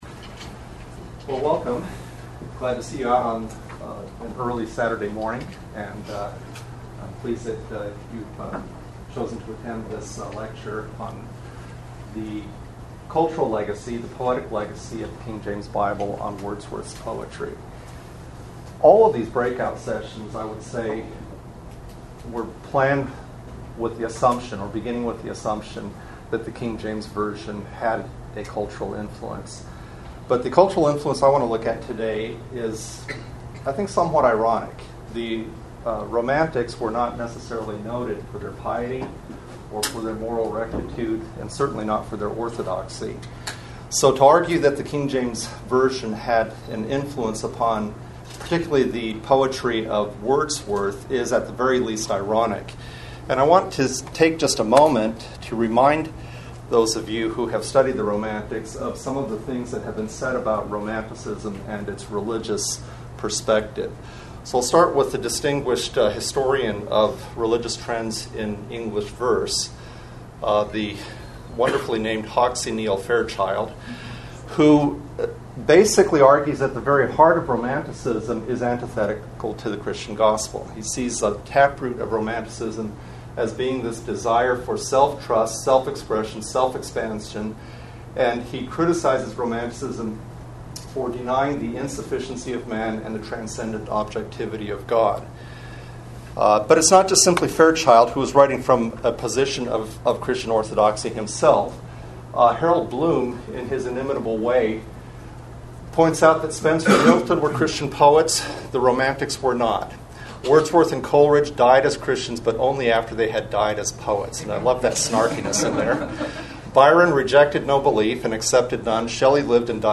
KJV400 Festival